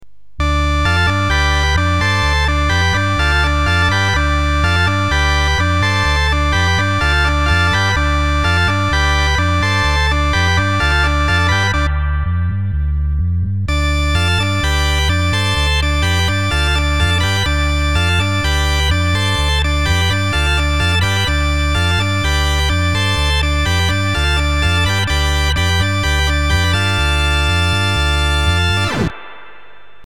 Demo4: Same riff, but 16, 8', 4' and 2 2/3' - 2'
Demo5: 16', 4' and 2 2/3' - 2' multitone-booster with all-booster on
Demo6: 16', 8', 4' and 2 2/3' - 2' multitone-booster with all-booster on (like demo 4)